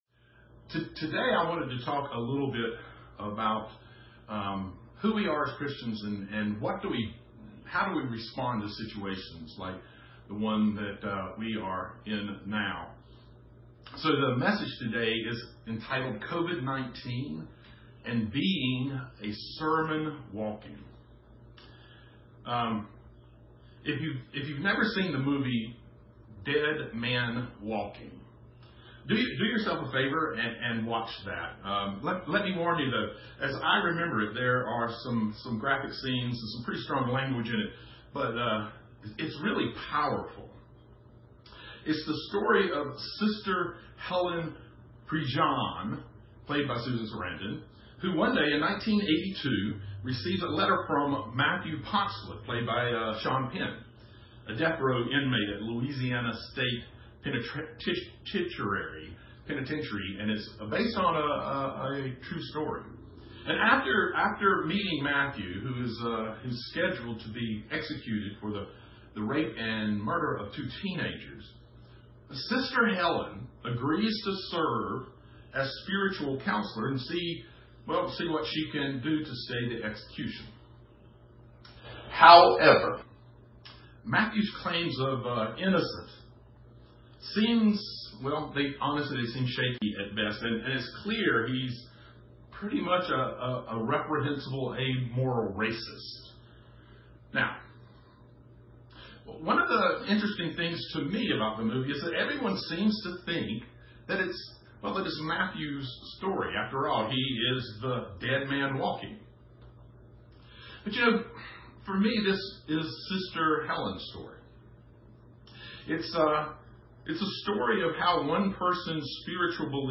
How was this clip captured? (streamed via Facebook)